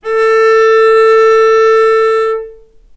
vc-A4-mf.AIF